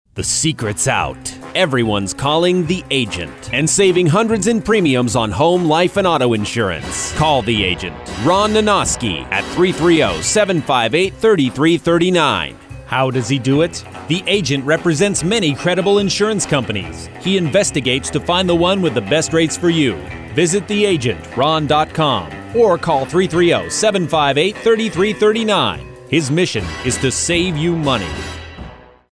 Radio Samples [.mp3]